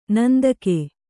♪ nandake